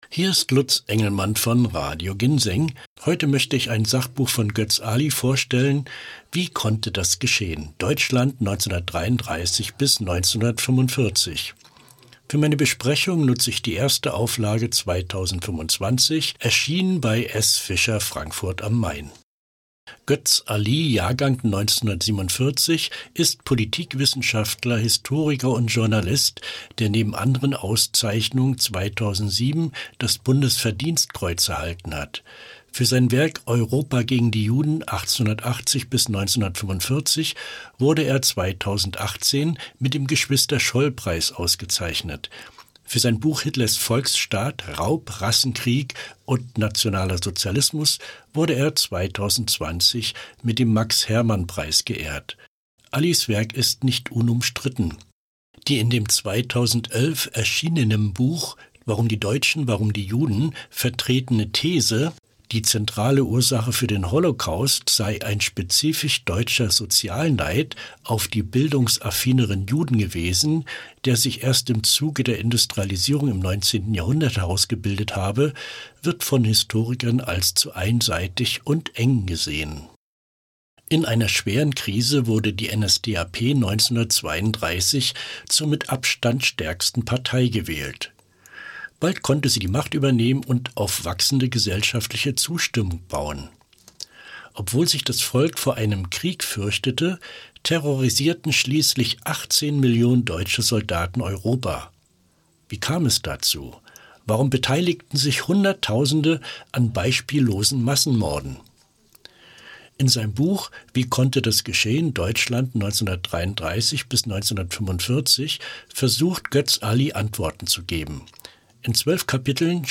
Sachbuchempfehlung